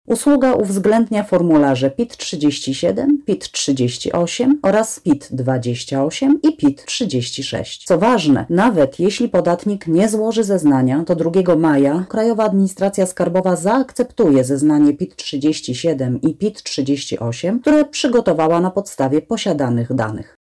• mówi Szef Krajowej Administracji Skarbowej Magdalena Rzeczkowska.